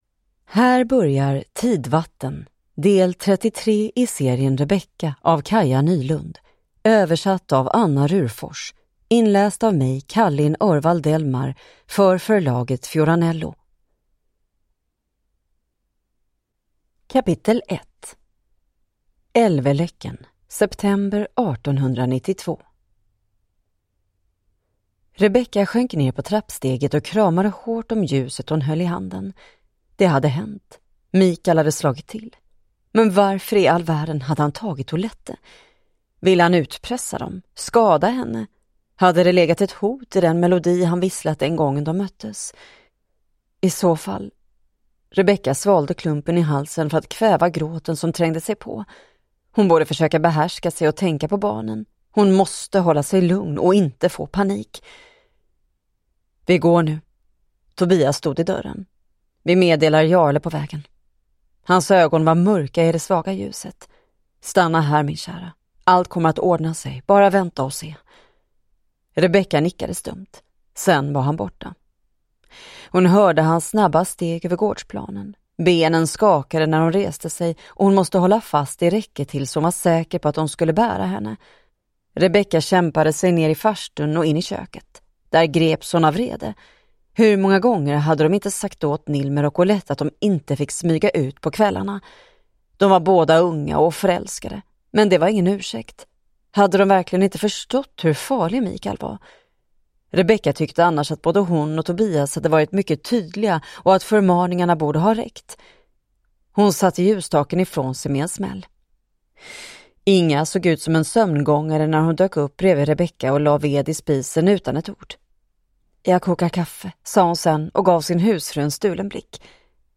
Tidvatten (ljudbok) av Kaja Nylund